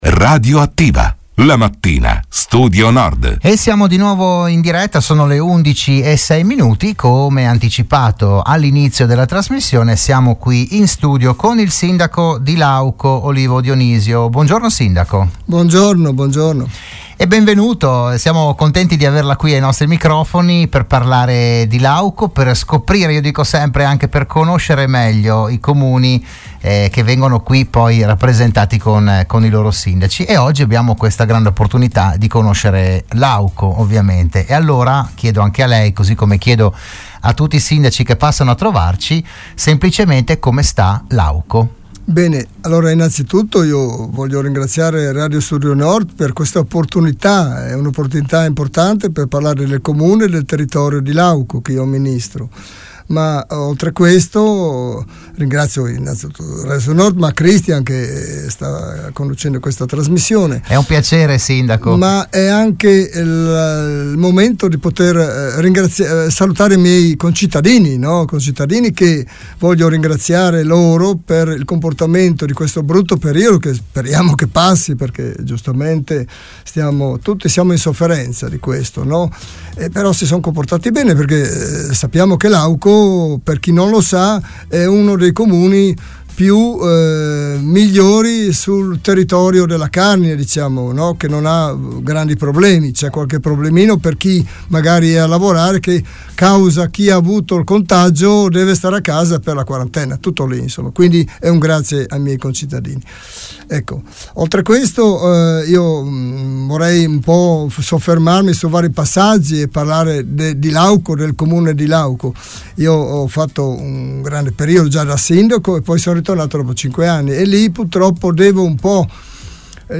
Ogni settimana il primo cittadino di un comune dell’Alto Friuli è ospite in studio, in diretta (anche video sulla pagina Facebook di RSN), per parlare del suo territorio, delle problematiche, delle iniziative, delle idee, eccetera.
All’undicesimo appuntamento del 2021 ha partecipato il sindaco di Lauco Olivo Dionisio.